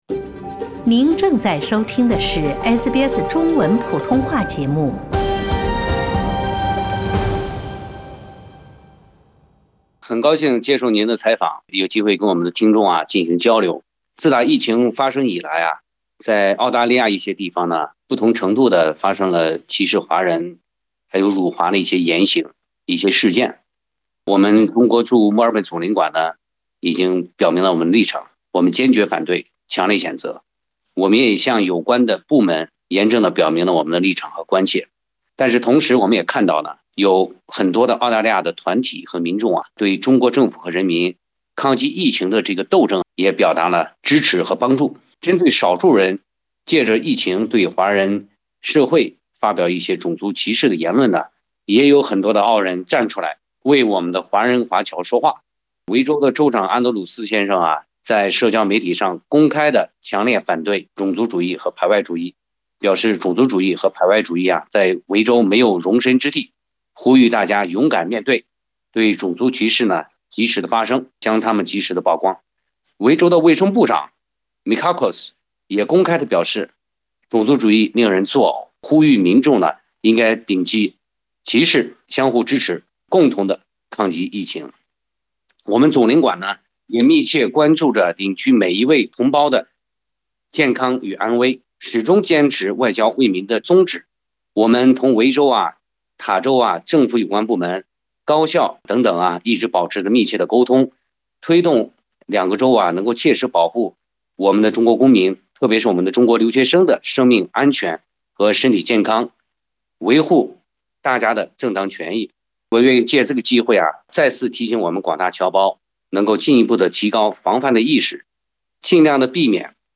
点击图片音频 收听本台对中国驻墨尔本总领事龙舟的专访。